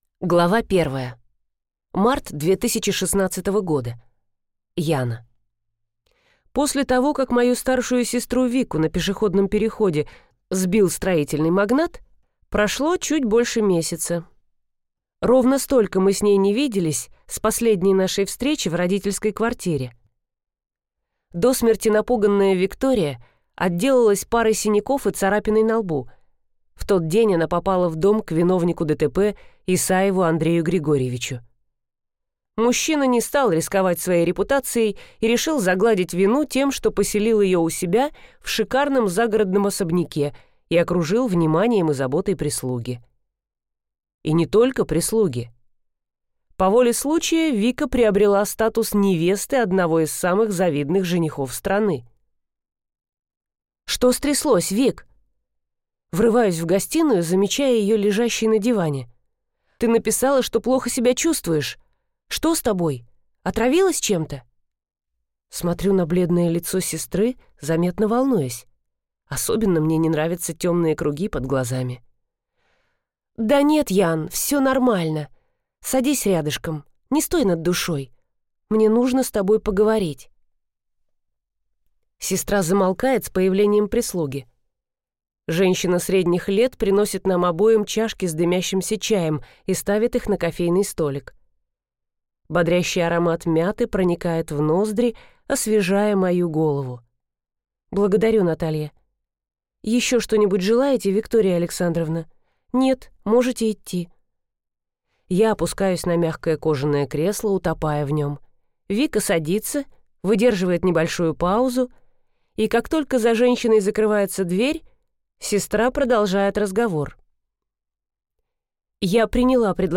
Аудиокнига Развернуться на скорости | Библиотека аудиокниг